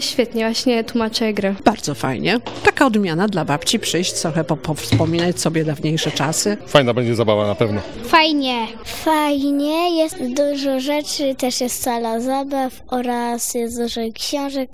Zapytaliśmy uczestników, dlaczego wybrali się na Noc Bibliotek oraz czy im się podobało.